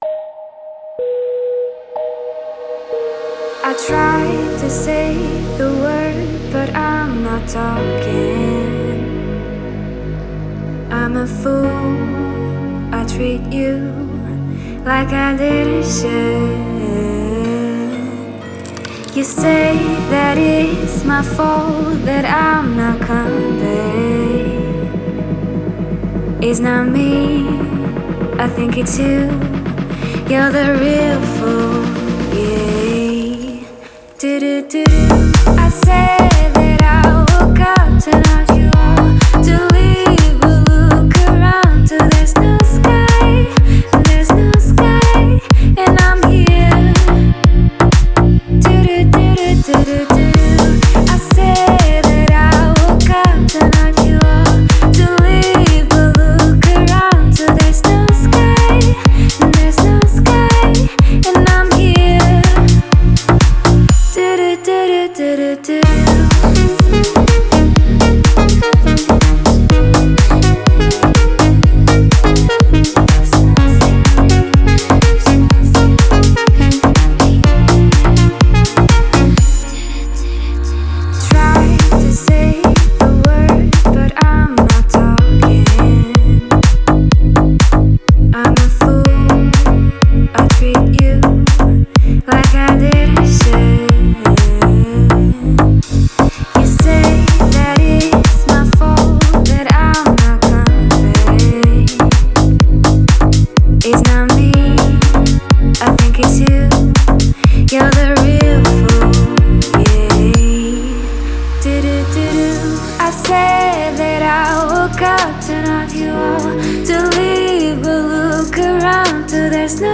это атмосферная композиция в жанре электронной музыки